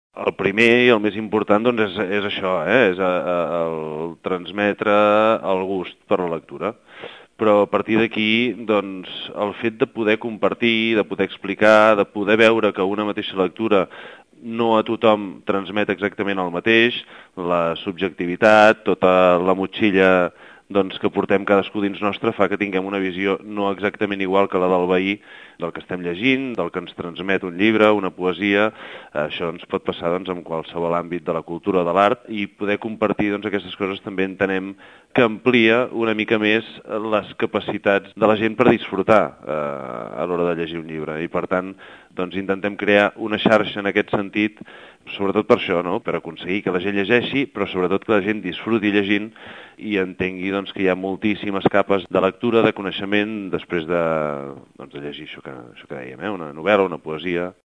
El regidor de cultura, Josep Llorens, diu que l’objectiu del club és que la gent s’aficioni a la lectura, però que també busca compartir les diferents sensacions que un llibre pot transmetre a cadascú.